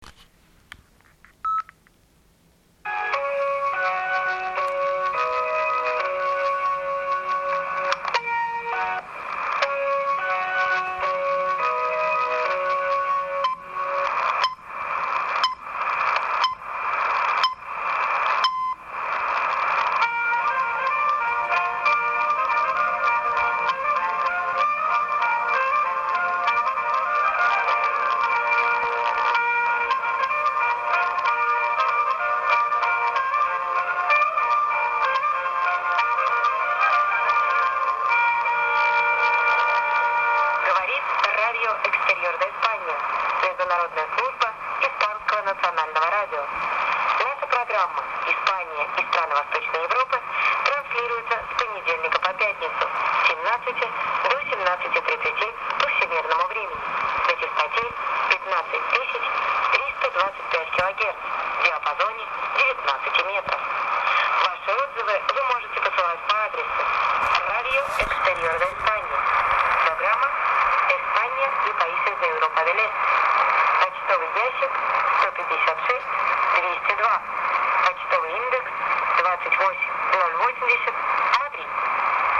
Interval Signal Sign On Audio